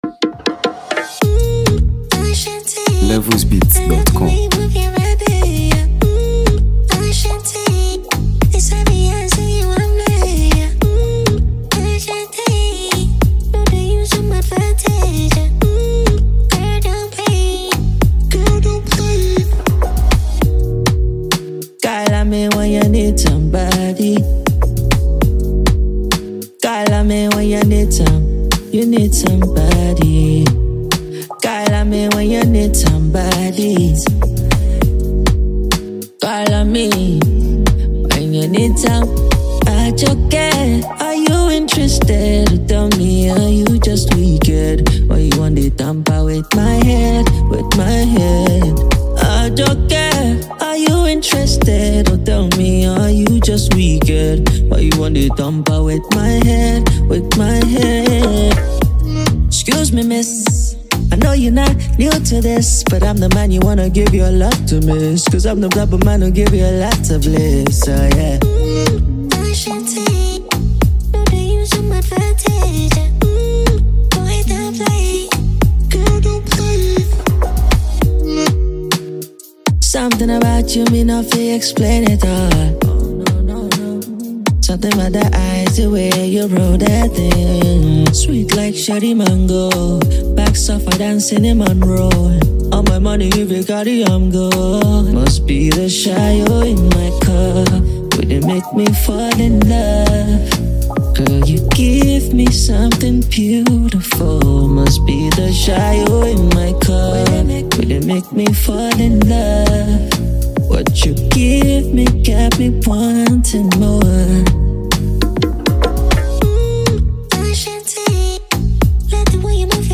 This enchanting record highlights his smooth vocals
Afrobeats